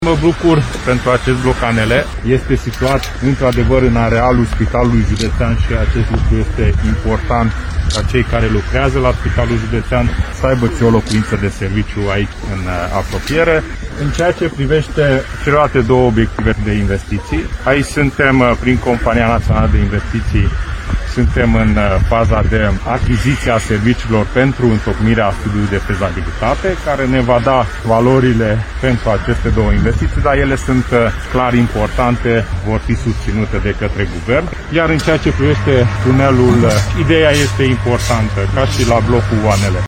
Ministrul Dezvoltării CSEKE ATILLA a spus că toate aceste proiecte se bucură de sprijinul Guvernului.